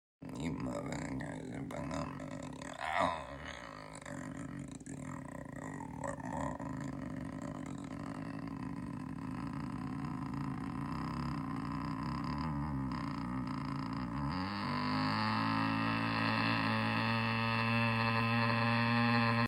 sounding like a motorcycle or sound effects free download
sounding like a motorcycle or a creaking door.